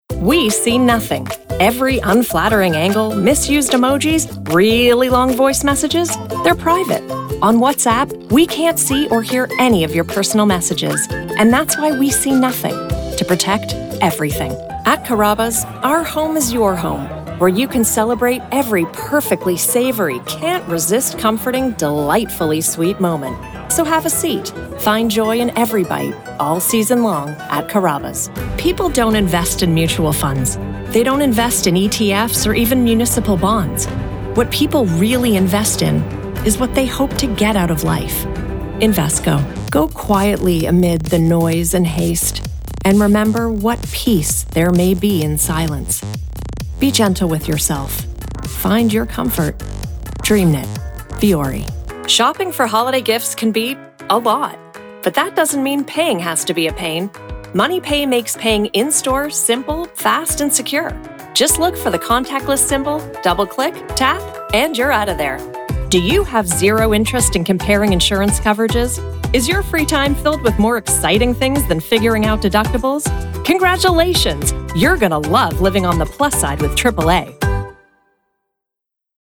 I bring clarity, warmth, and a voice that truly connects—so your message lands the way you intended.
Commercial Demo
🎙 Broadcast-quality audio
(RODE NT1 Signature Mic + Fully Treated Studio)
Middle Aged Female